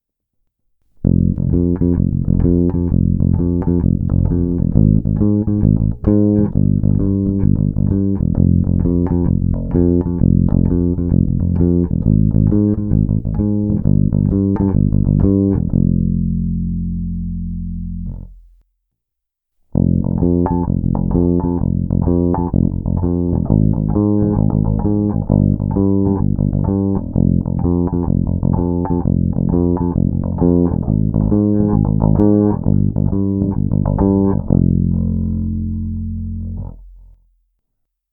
Osobně jsem dlouho laboroval, ale nakonec jsem si oblíbil jako hodnější zvuk pasívní režim s trochu staženou tónovou clonou a pro zlobivější zvuk pak z filtrů pozici číslo 3.